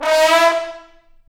Index of /90_sSampleCDs/Roland L-CDX-03 Disk 2/BRS_Bone Sec.FX/BRS_Bone Sec.FX